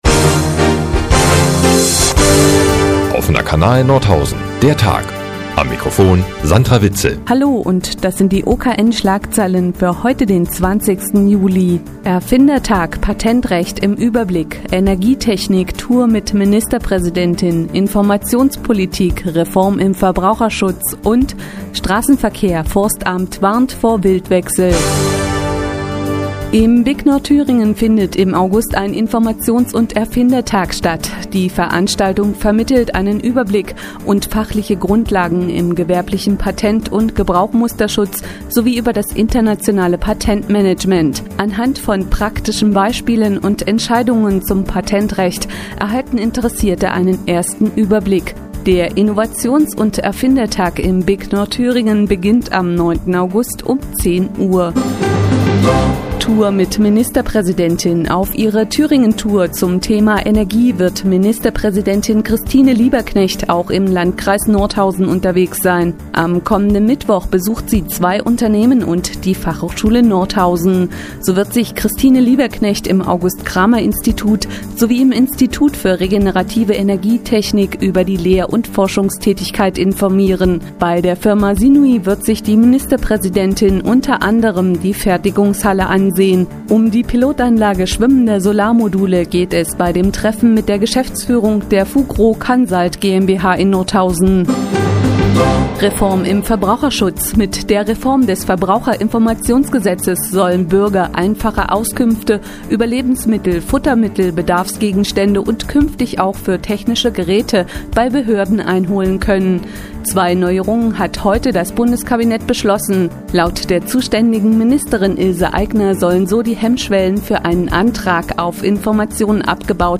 20.07.2011, 15:30 Uhr : Seit Jahren kooperieren die nnz und der Offene Kanal Nordhausen. Die tägliche Nachrichtensendung des OKN ist jetzt hier zu hören.